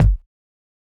Kick (5).wav